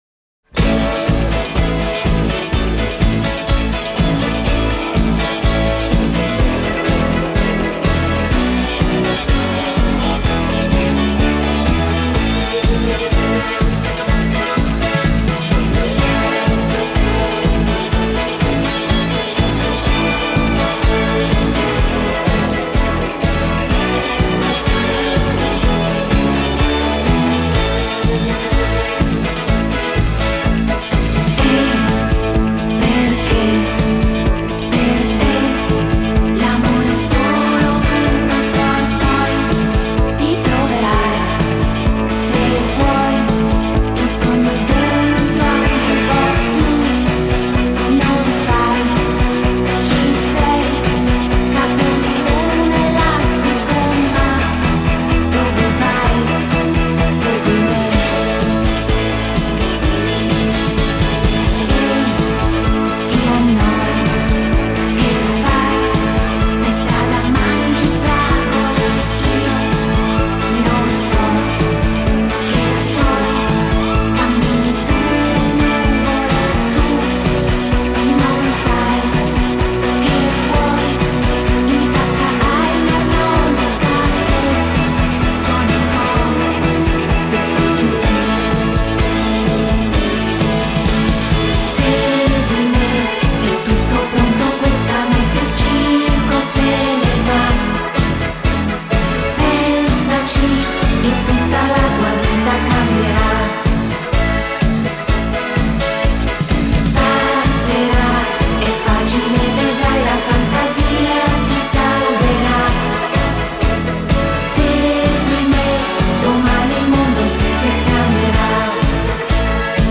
Genre: Pop, Disco,Nu-Disco,Dance